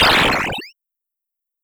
Track 05 - Synth Blurble OS 02.wav